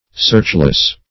Searchless \Search"less\
searchless.mp3